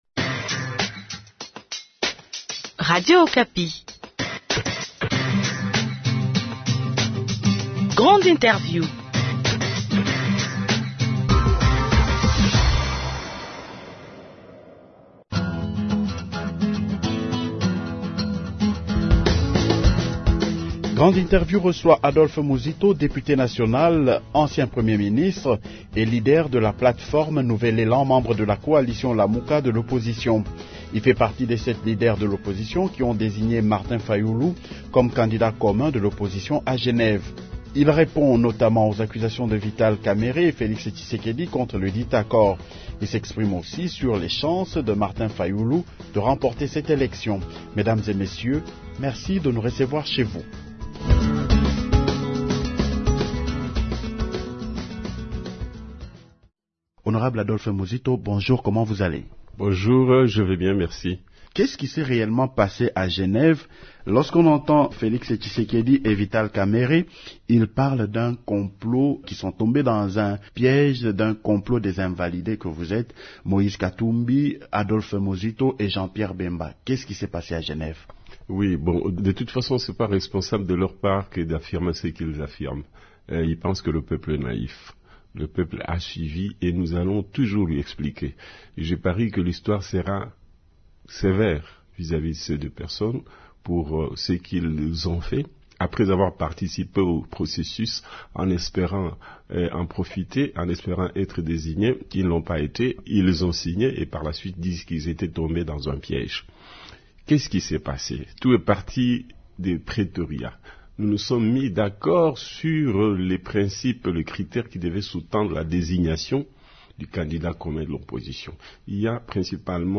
Grande interview reçoit Adolphe Muzito, député national, ancien premier ministre,leader de la plateforme « Nouvel Elan » membre de la coalition « Lamuka » de l’opposition.